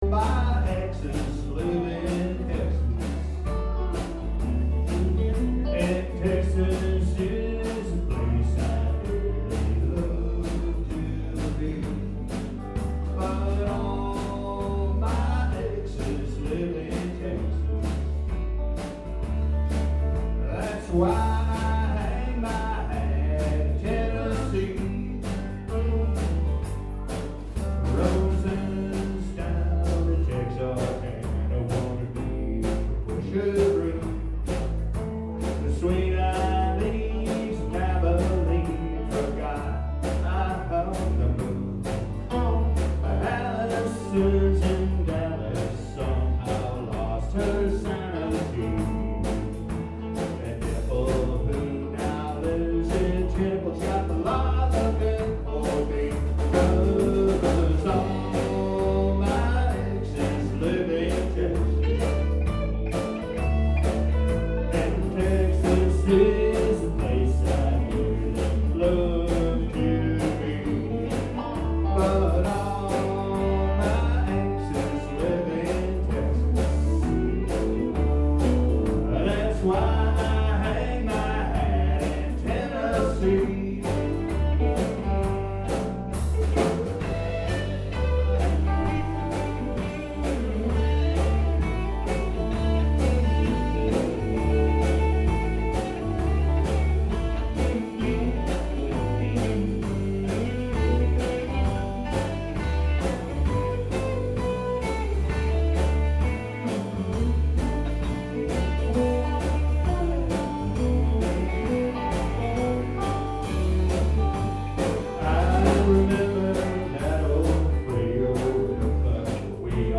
Bad Motor Scooter Band performs at Jollie's Lounge, April 11, 2014